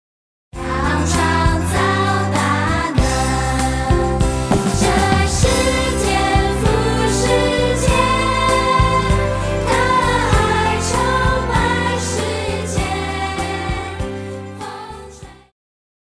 Christian
Pop chorus,Children Voice
Band
Hymn,POP,Christian Music
Voice with accompaniment
為了淺顯易懂，除了把現代樂風融入傳統聖詩旋律，針對部份艱澀難懂的歌詞，也稍作修飾，儘可能現代化、口語化；